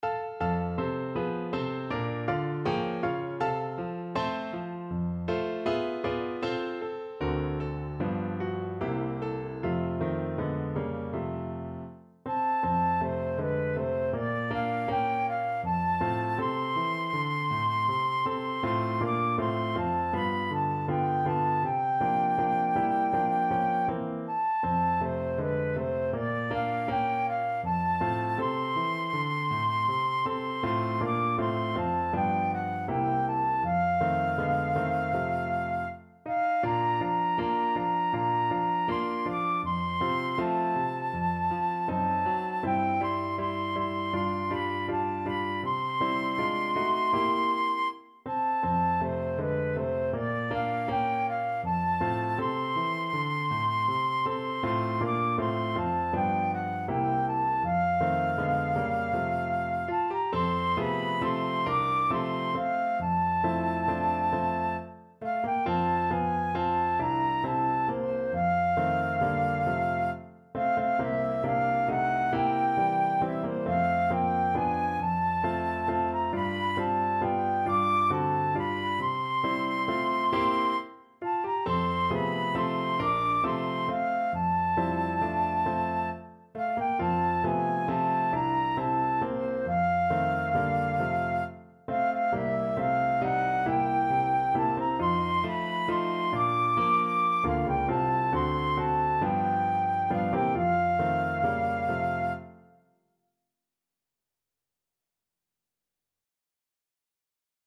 Andante
4/4 (View more 4/4 Music)
Pop (View more Pop Flute Music)